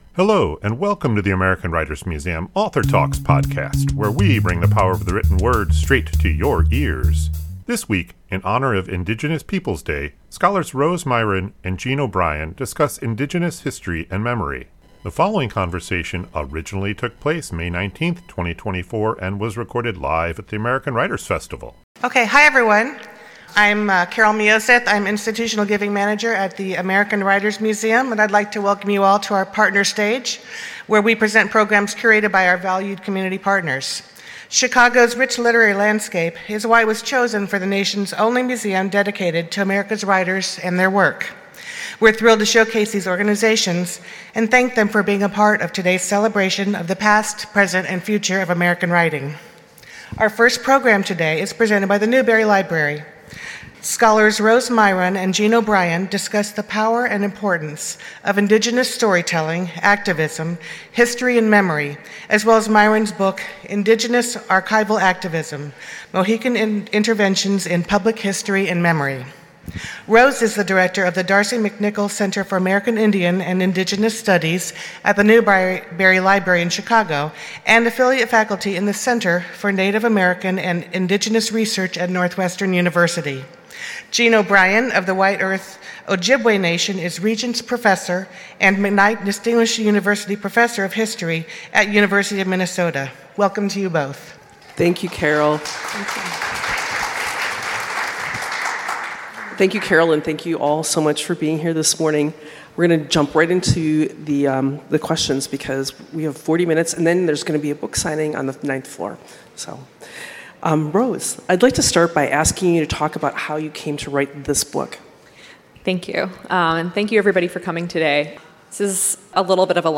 This conversation originally took place May 19, 2024 and was recorded live at the American [...]